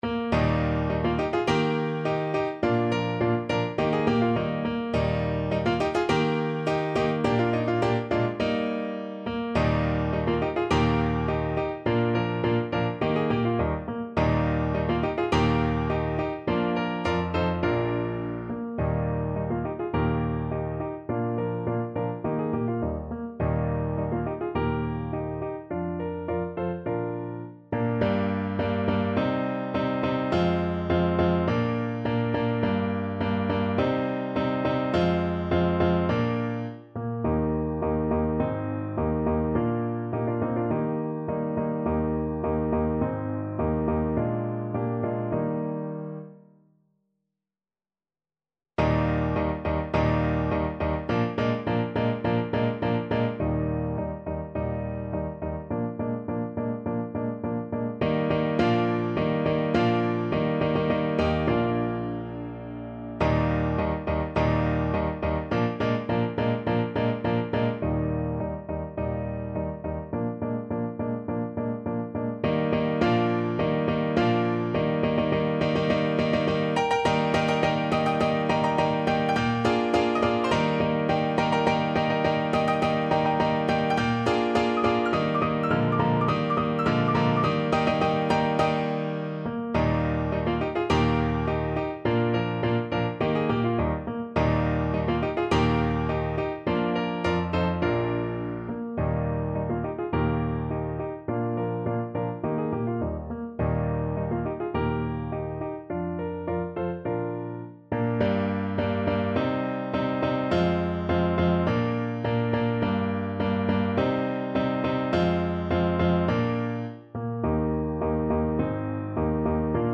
~ = 100 Molto vivace =104
2/4 (View more 2/4 Music)
Classical (View more Classical Saxophone Music)